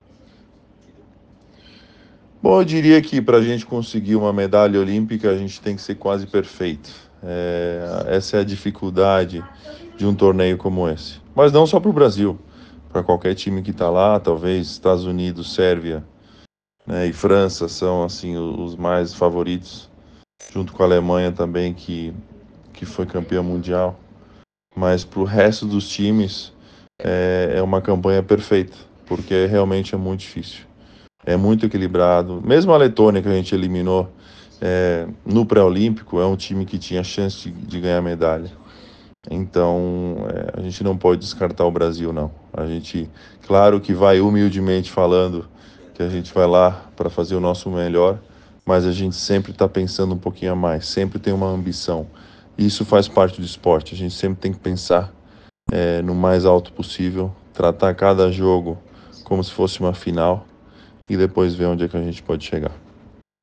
Entrevista com Tiago Splitter – Auxiliar técnico da Seleção Brasileira de Basquete